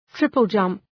triple-jump.mp3